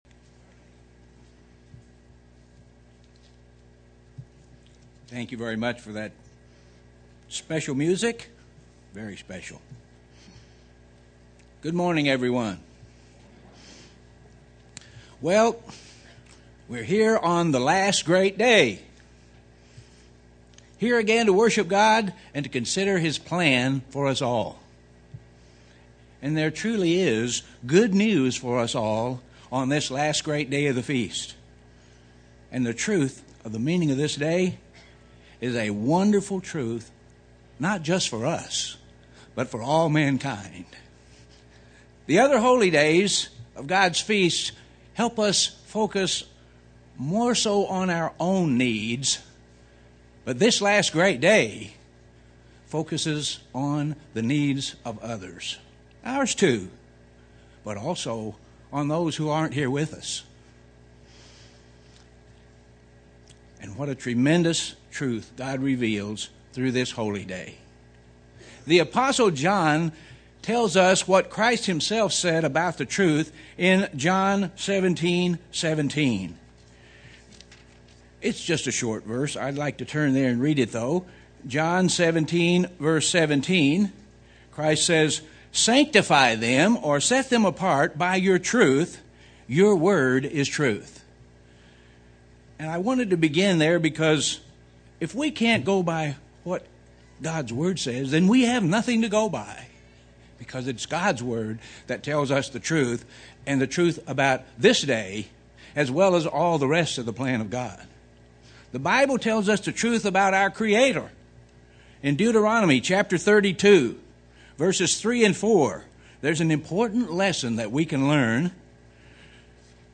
This sermon was given at the Branson, Missouri 2011 Feast site.